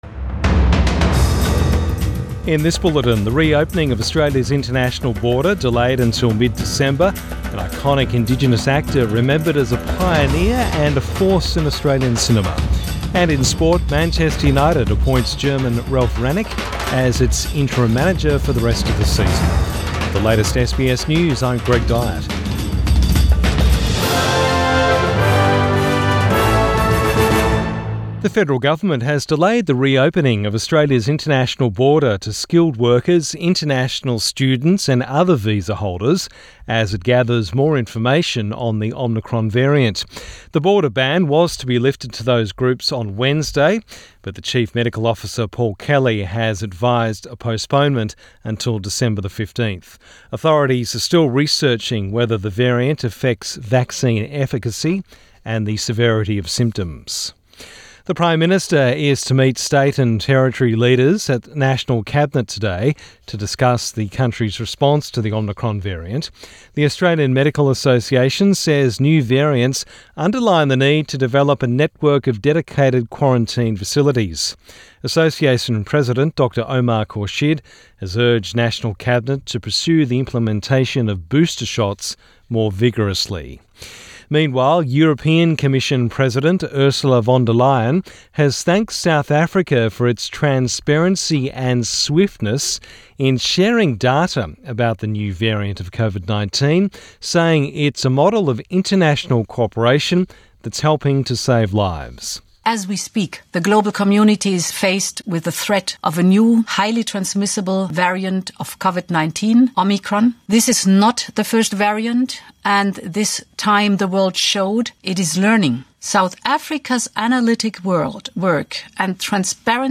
AM bulletin 30 November 2021